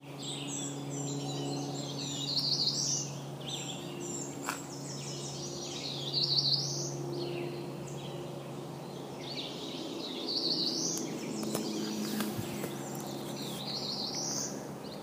Here is a brief excerpt of the Cerulean Warbler singing this morning:
Cerulean Warbler, Waterloo Valley Rd., NJ